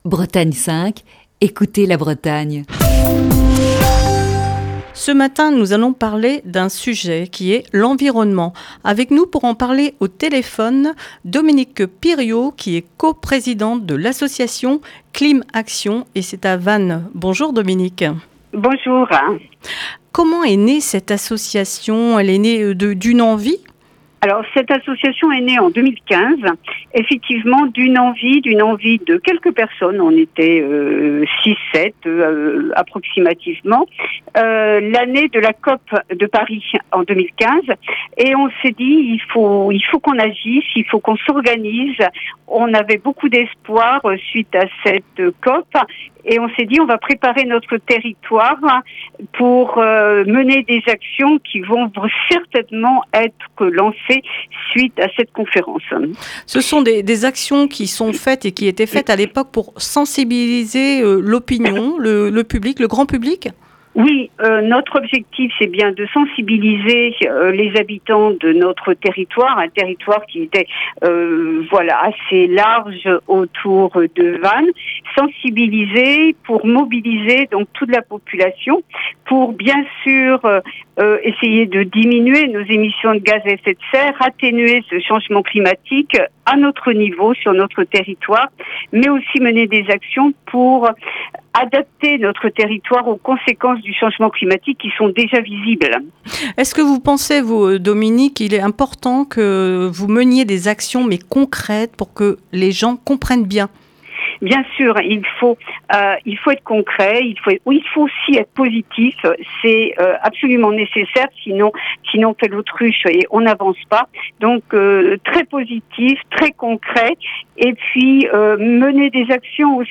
est au téléphone